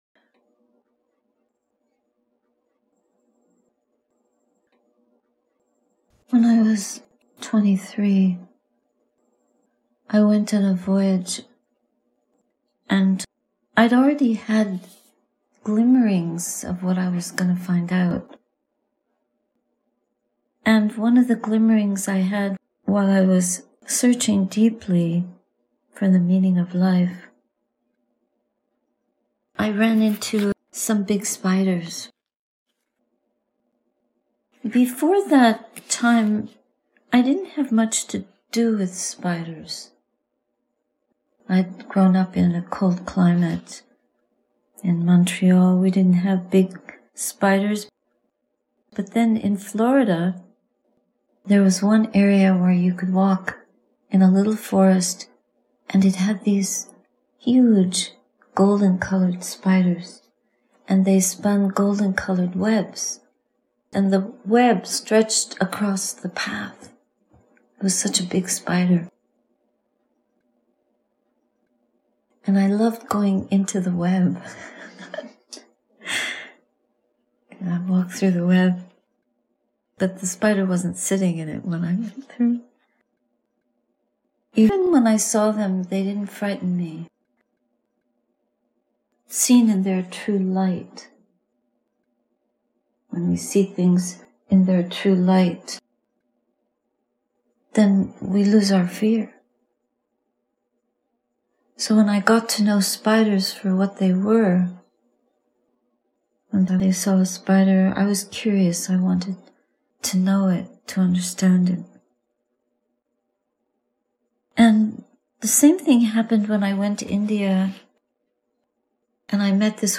Breakfast Reflections, Sati Saraniya Hermitage, Aug. 12, 2025